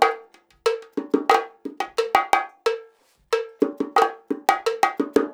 90 BONGO 2.wav